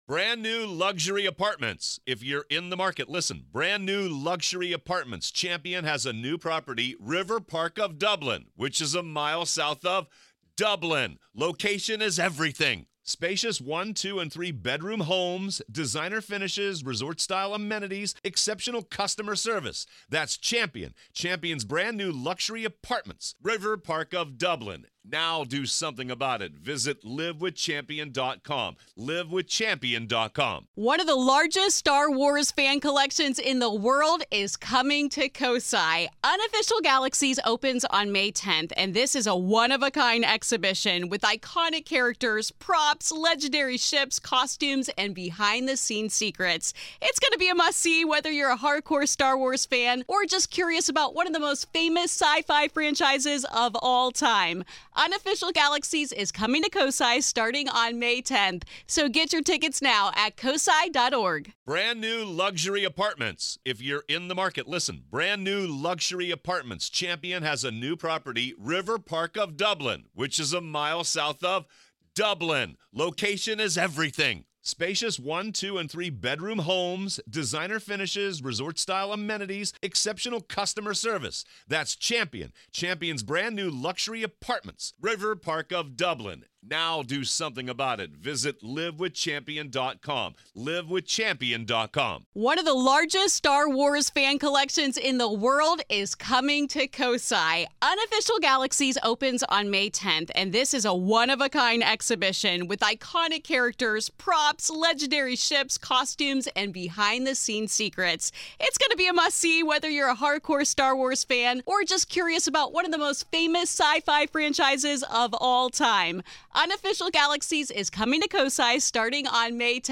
Part Two of our conversation about investigations, advice to novice ghost hunters, equipment, locations, and more